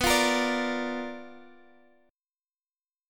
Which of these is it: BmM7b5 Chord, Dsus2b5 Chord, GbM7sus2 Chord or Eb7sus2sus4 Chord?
BmM7b5 Chord